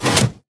Sound / Minecraft / tile / piston / in.ogg